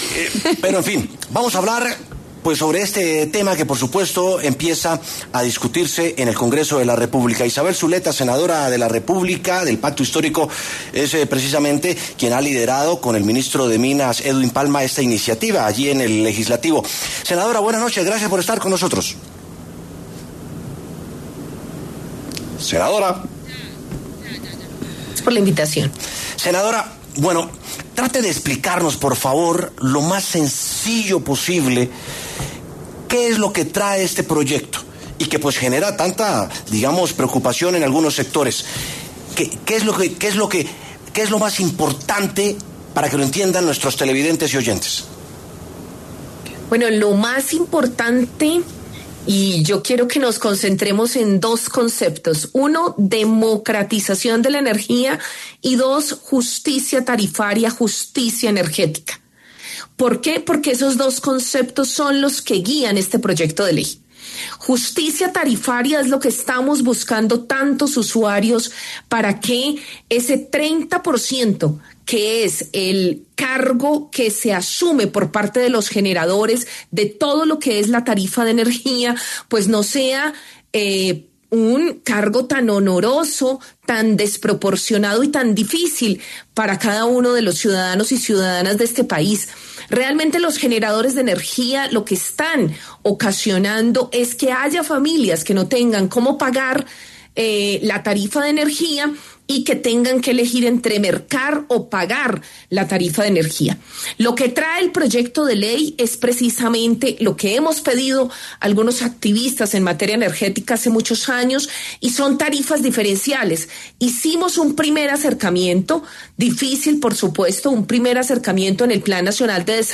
En W Sin Carreta, los senadores Isabel Zuleta y José Antonio Correa analizaron al detalle este proyecto.